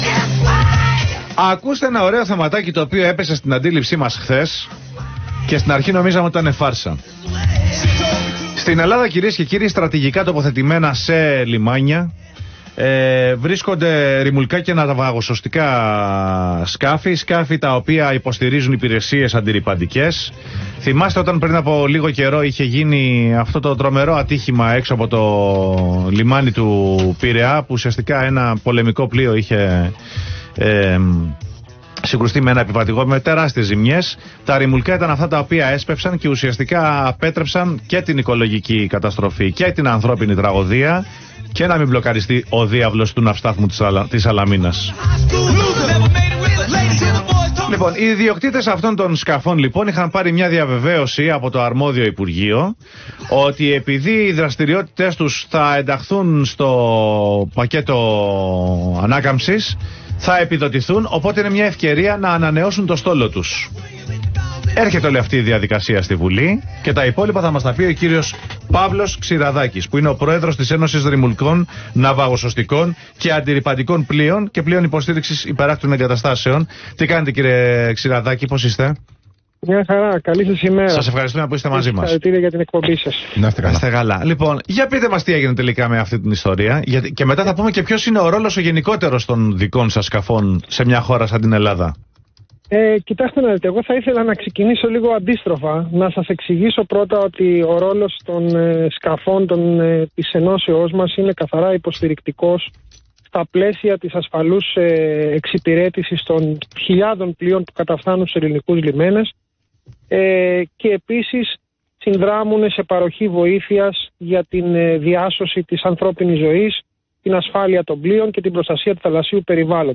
Ραδιοφωνική Συνέντευξη στον ALPHA FM